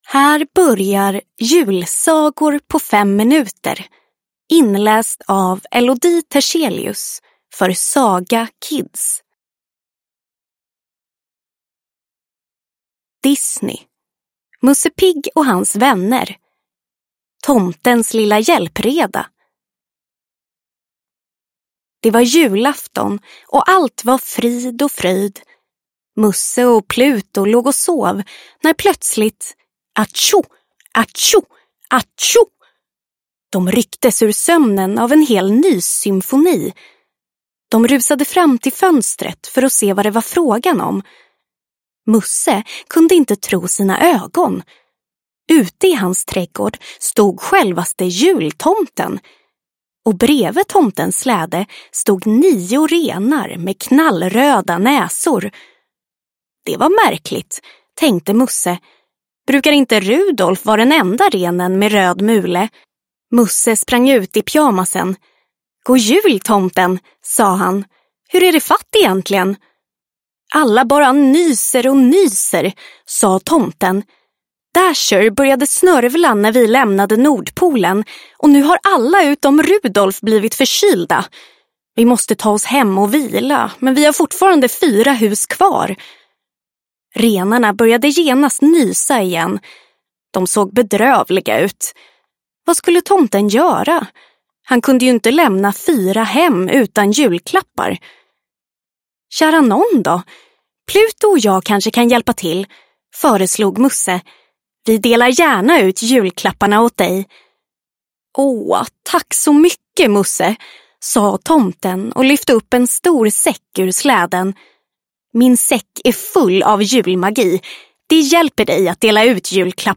Fem minuter till jul – Ljudbok